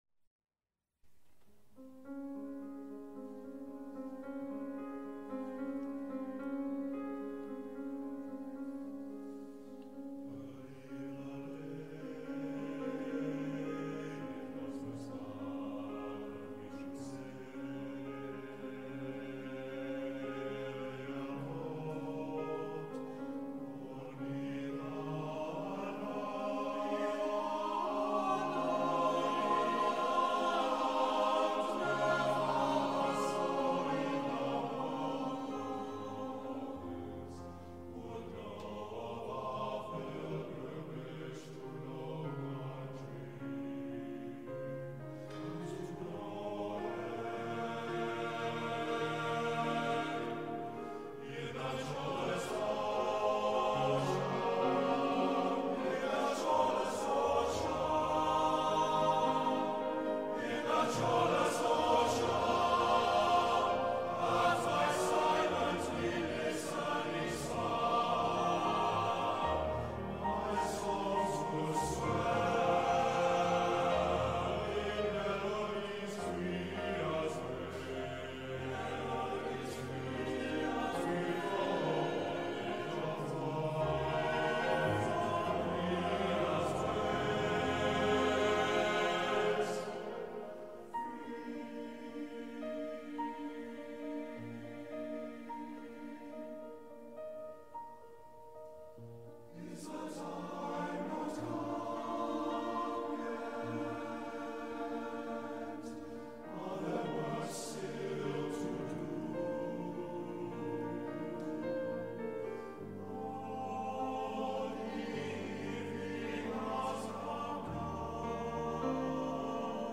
TTBB, piano